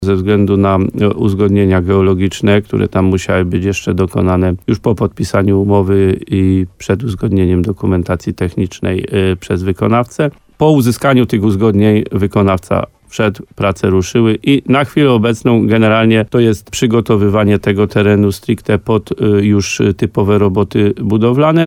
– Mimo tego, że umowa z wykonawcą została podpisana na początku tamtego roku, to prace rozpoczęły się z kilkumiesięcznym opóźnieniem – przyznaje wójt gminy Łososina Dolna, Adam Wolak.